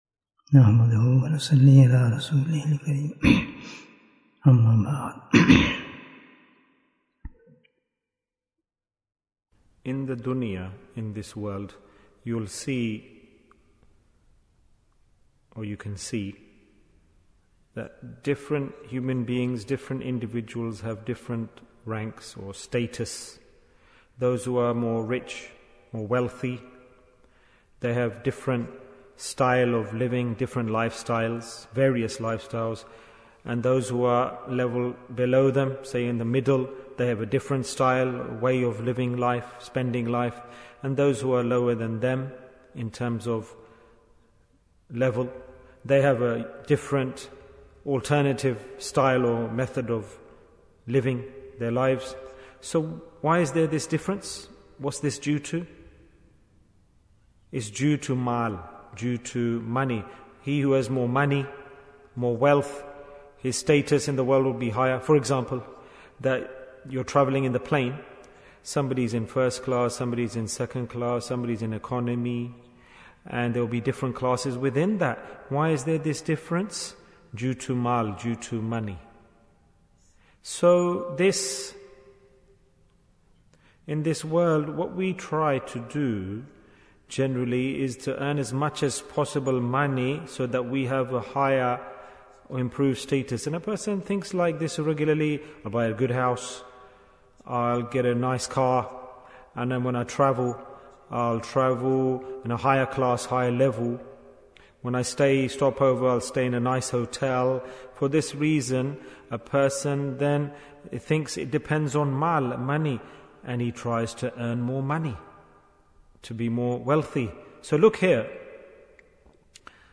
Who is Superior? Bayan, 22 minutes21st May, 2023